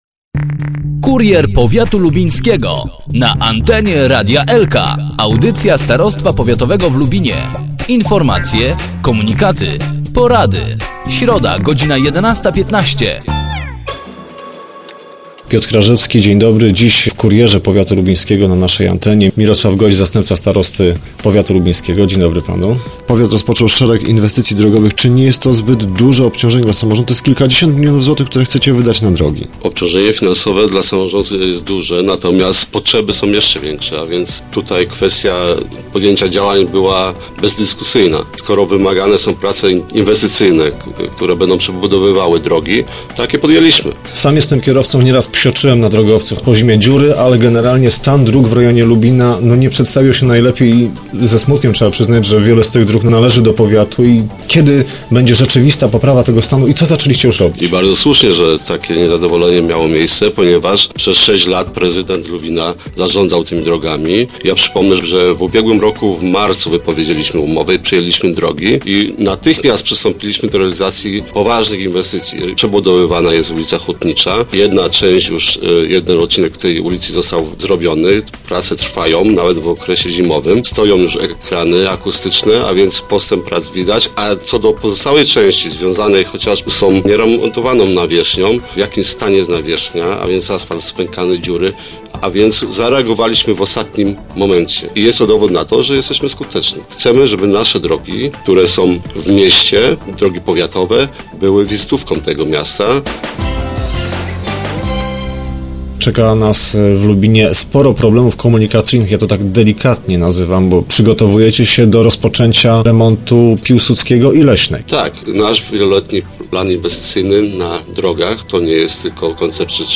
Gościem Kuriera Powiatu Lubińskiego jest Mirosław Gojdź, zastępca starosty, członek zarządu powiatu.